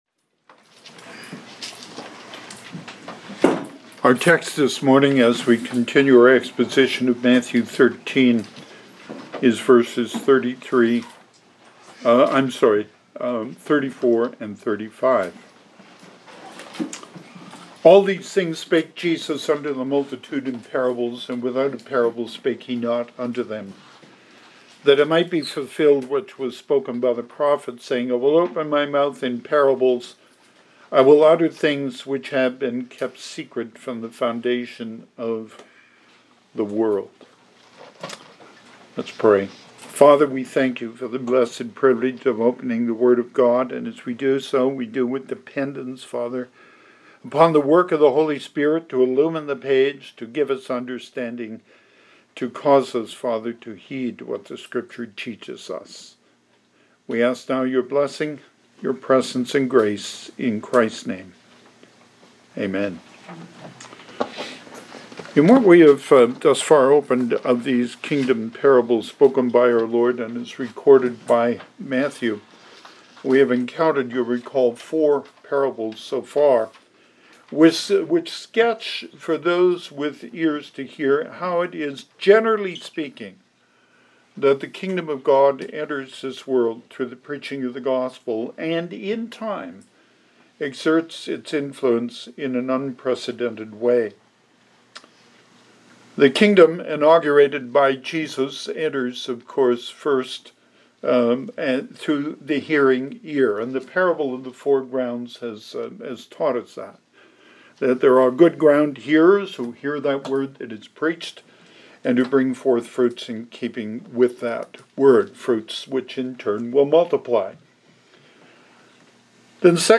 Passage: Matthew 13:34-35 Service Type: Sunday AM